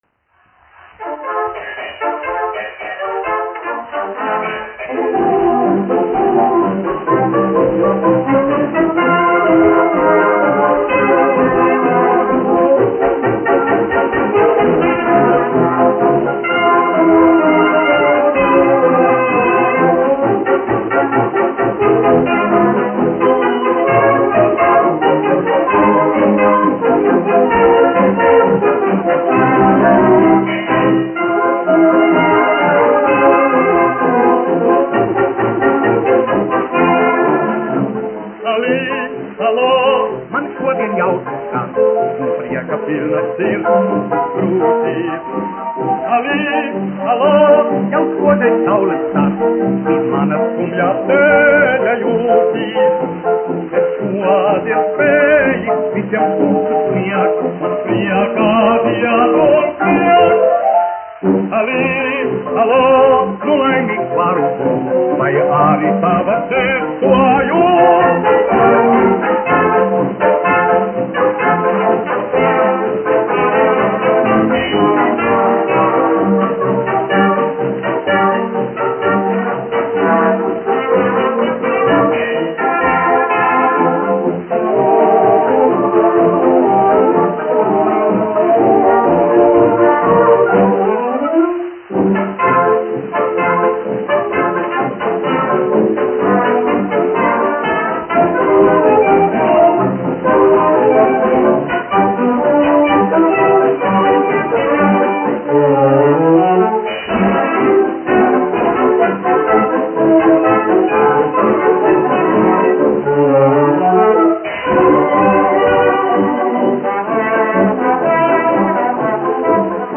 1 skpl. : analogs, 78 apgr/min, mono ; 25 cm
Fokstroti
Populārā mūzika
Skaņuplate
Latvijas vēsturiskie šellaka skaņuplašu ieraksti (Kolekcija)